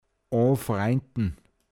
pinzgauer mundart
o(n)fraintn anfreunden